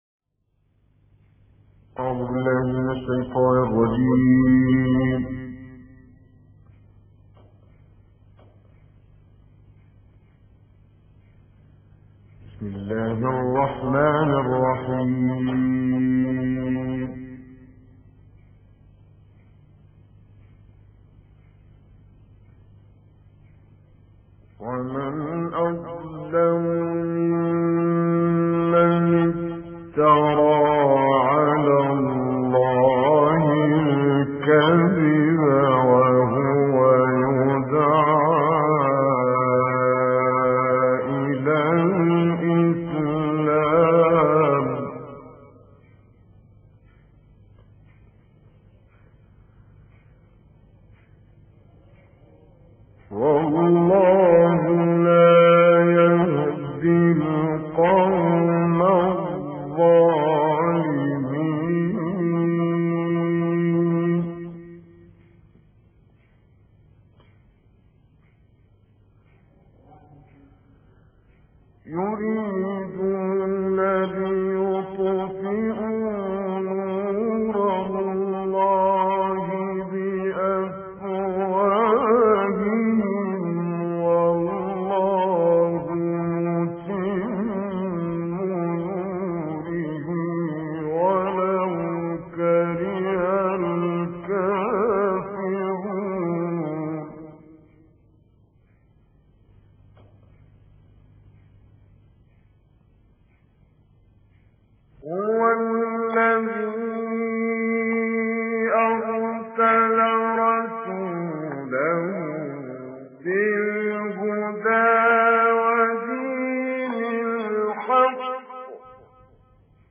گروه فعالیت‌های قرآنی: تلاوت کوتاه و استودیویی کامل یوسف البهتیمی که در دهه 60 میلادی اجزا شده است، می‌شنوید.
به گزارش خبرگزاری بین المللی قرآن(ایکنا) تلاوت آیات 7 تا 11 سوره صف با صوت شیخ کامل یوسف البهتیمی، قاری برجسته مصری که در دهه 60 میلادی اجرا شده است در کانال تلگرامی پایگاه قرآنی تلحین منتشر شده است.
مدت زمان این تلاوت که در استودیو ضبط شده است، 4 دقیقه و 52 ثانیه است.